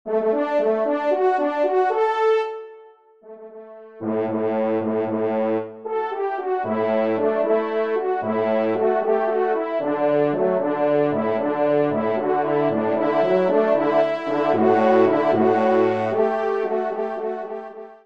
Trompe Basse